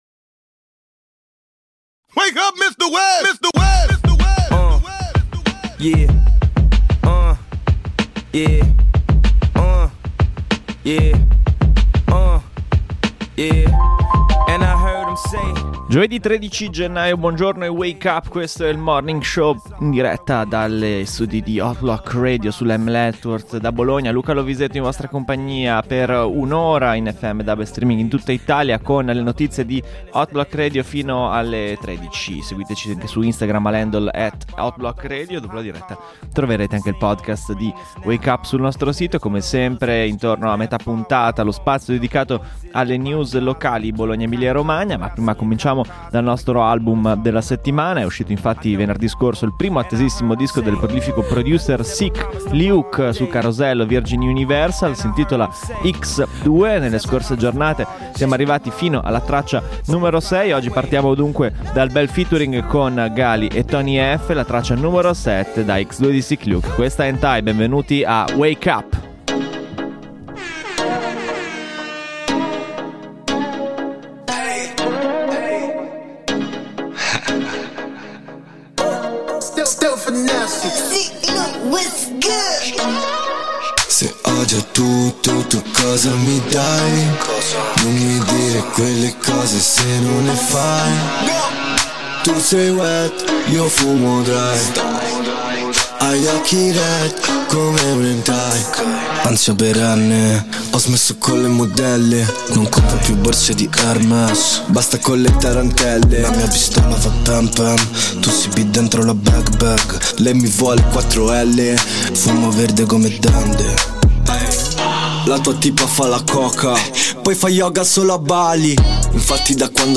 in diretta sull'ML Network dalla redazione bolognese di Hot Block Radio